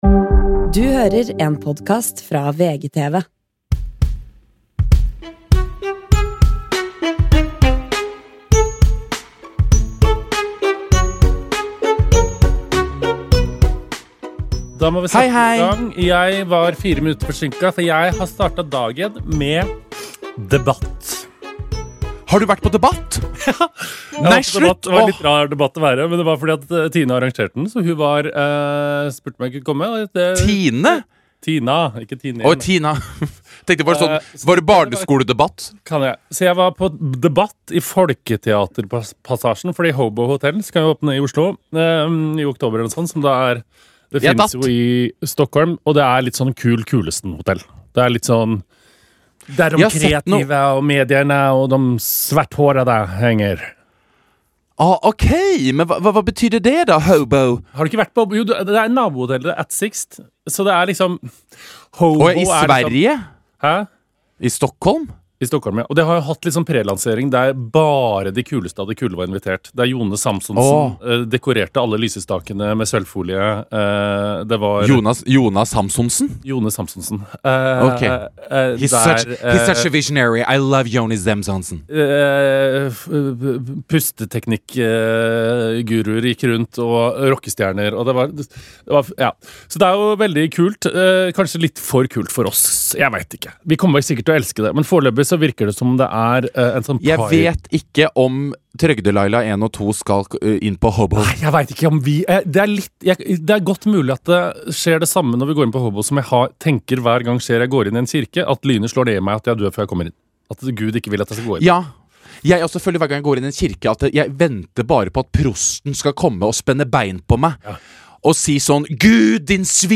… continue reading 338 एपिसोडस # Samtaler # Samfunn